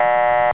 buzzer.mp3